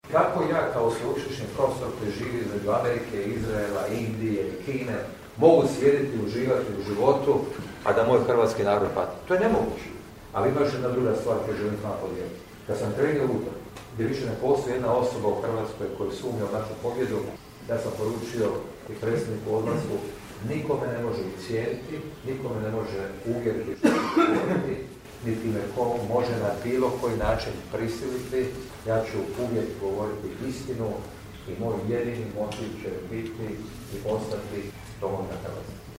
Započeo ju je u Garešnici, gdje se sastao s članovima Gradskog odbora stranke i građanima u Centru za posjetitelje.